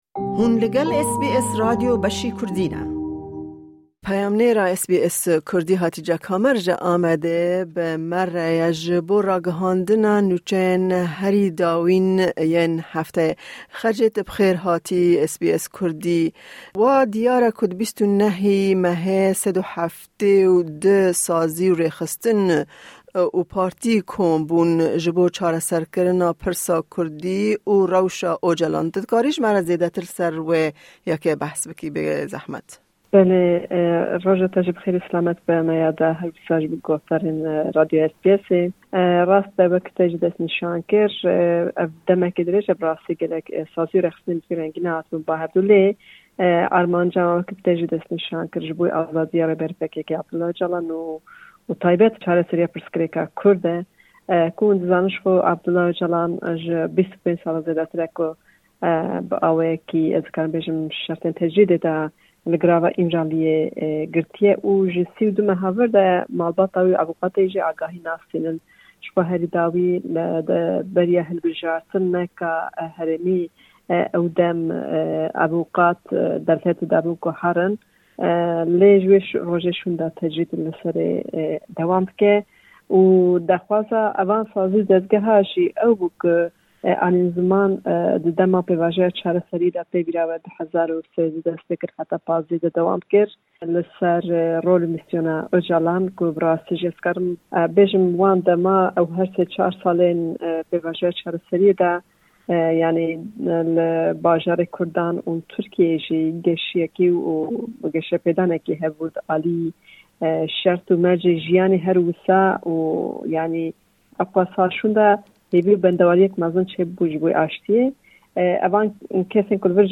Her weha di raportê de behs li 100 saliya Komara Tirkiyê dibe, ku ev ji bo Kurdan destpêka gelek pşrsgirêkan e.